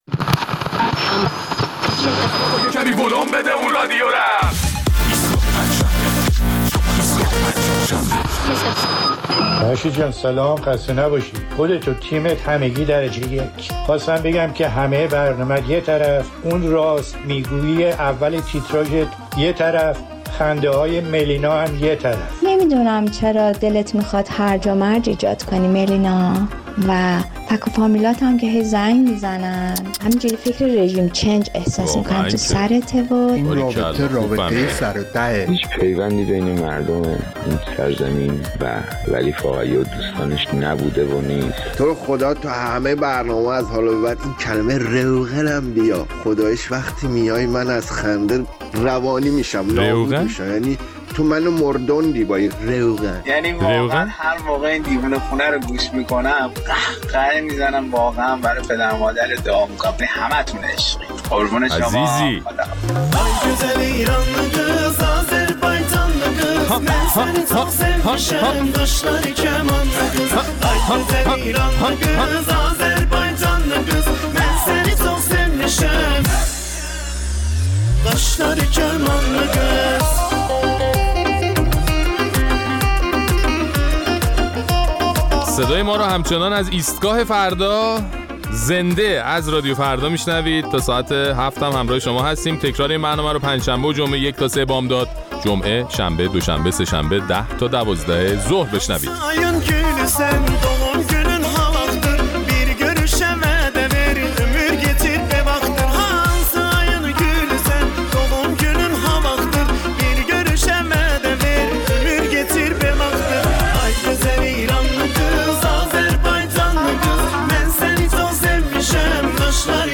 در این برنامه ادامه نظرات شنوندگان ایستگاه فردا را در مورد پیام شاهزاده رضا پهلوی و نظر علی خامنه‌ای که مردم ایران را انقلابی‌تر و دیندارتر از اول انقلاب می‌دانست، می‌شنویم.